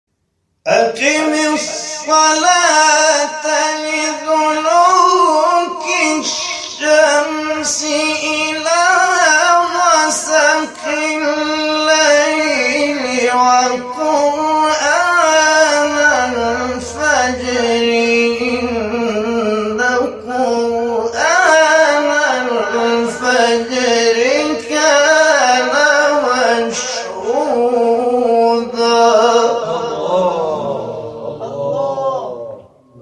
شبکه اجتماعی: فرازهای صوتی از تلاوت قاریان ممتاز و بین المللی کشور که به تازگی در شبکه‌های اجتماعی منتشر شده است، می‌شنوید.
سوره مبارکه اسراء به سبک شعیشع